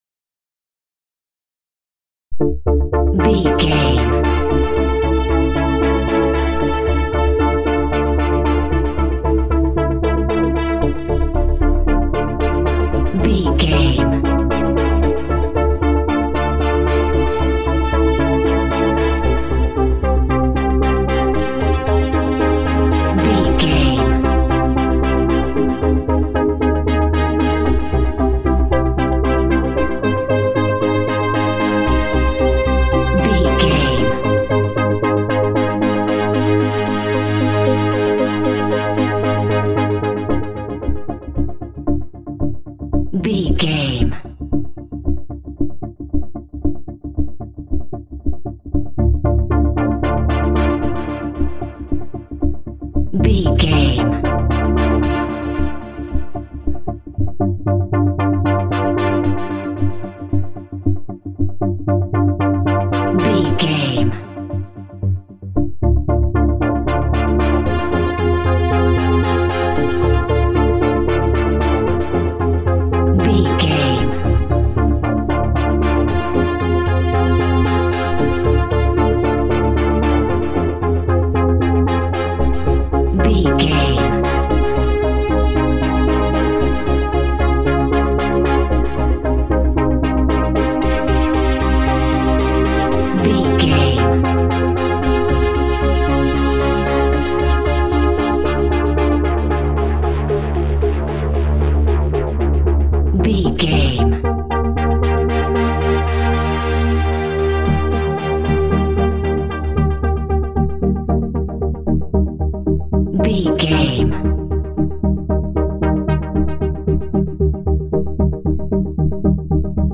In-crescendo
Aeolian/Minor
D
tension
ominous
dark
haunting
eerie
synthesiser
Horror synth
Horror Ambience
electronics